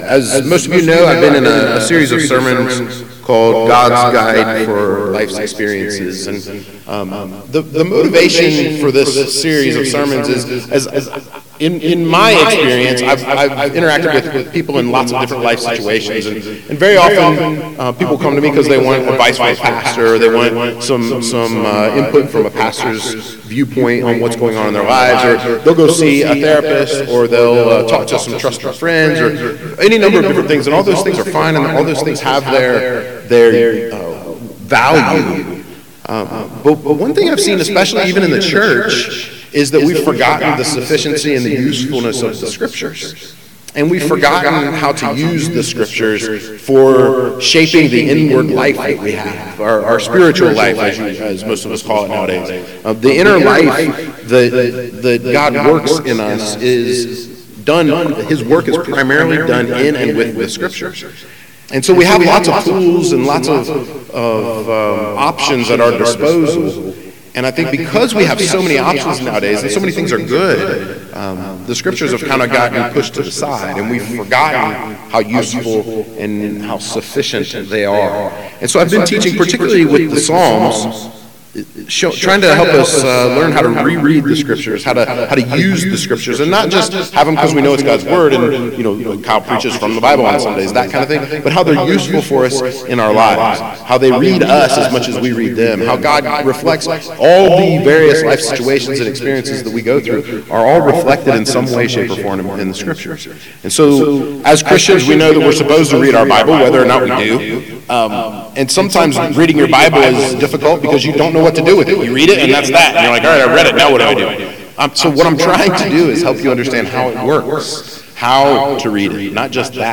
gods-guide-for-lifes-experiences-sermon-5-psalm-19.mp3